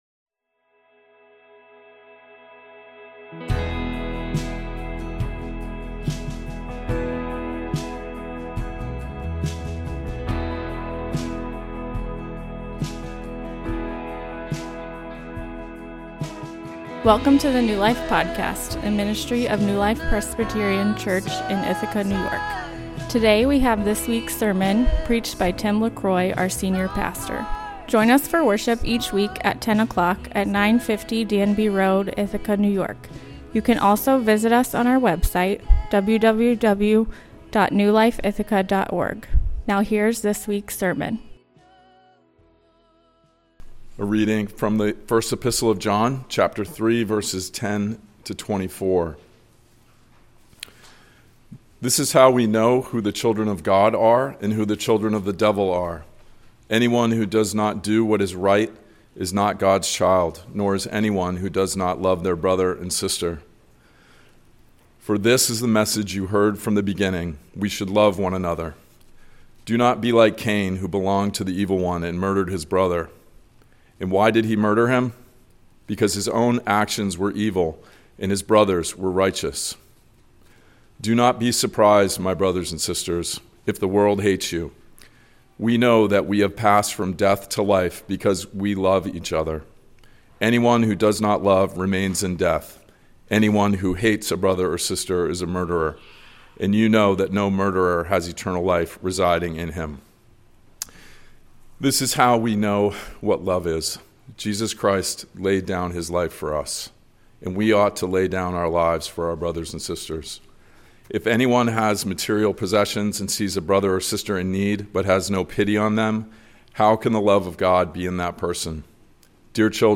A sermon from 1 John 3:10-24 Outline: I. Two Ways II.